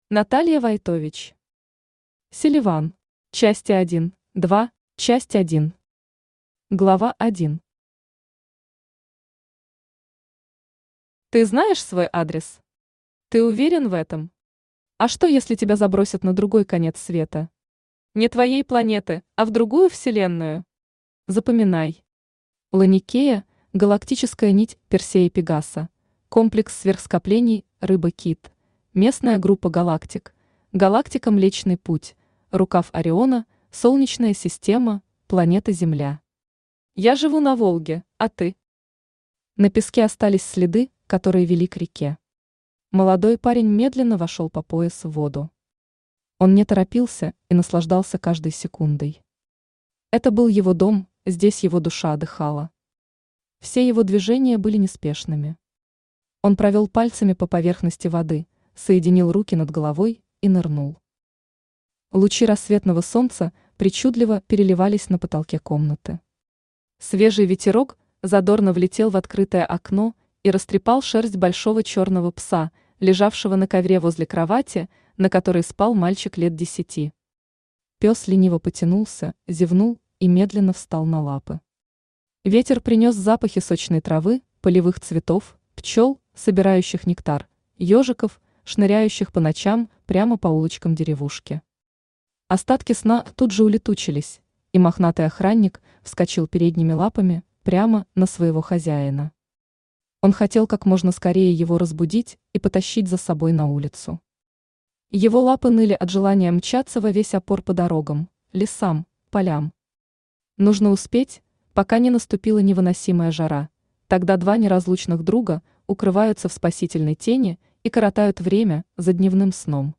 Аудиокнига Селиван. Части 1, 2 | Библиотека аудиокниг
Части 1, 2 Автор Наталья Войтович Читает аудиокнигу Авточтец ЛитРес.